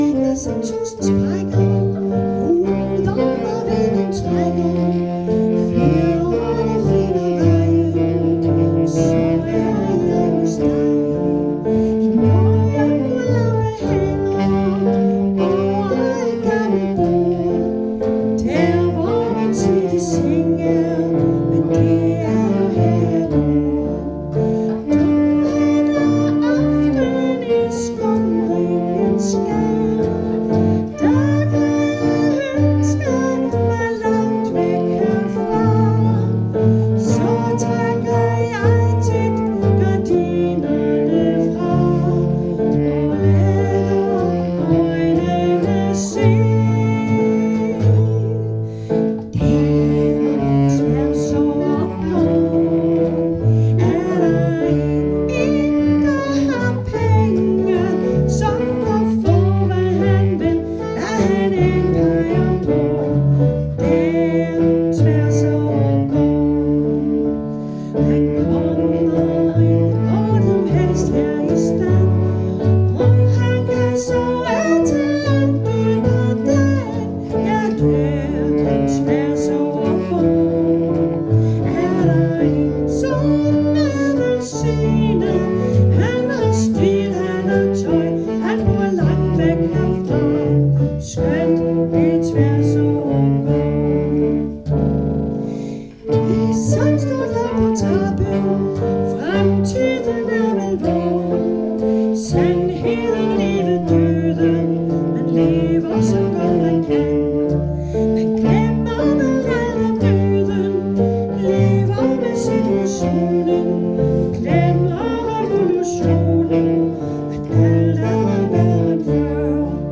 Øveaften 23. oktober 2024: